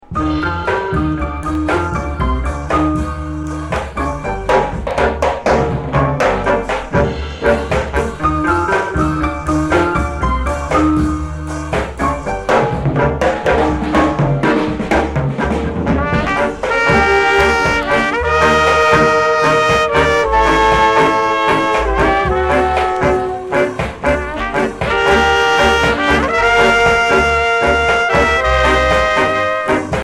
Reggae Ska Dancehall Roots ...